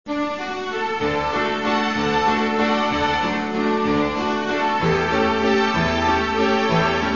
Valzer.